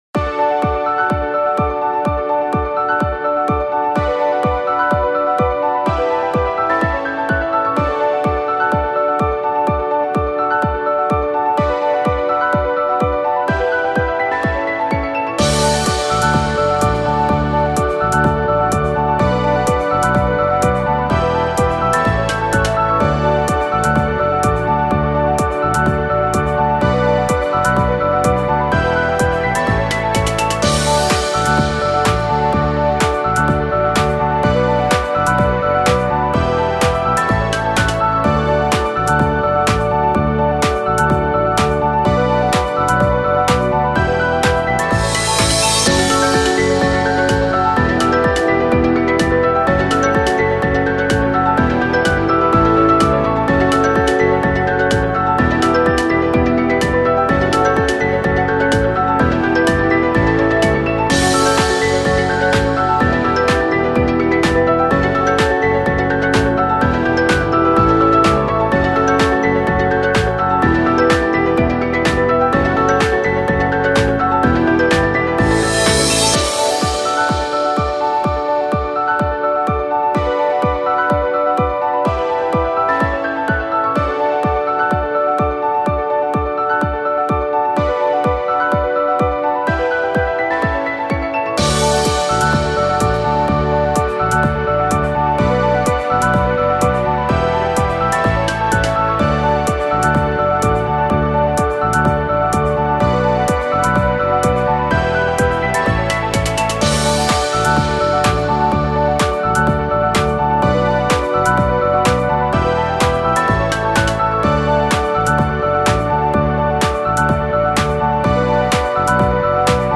高音のピアノが綺麗に響く、爽やかな雰囲気のコーポレートBGMです。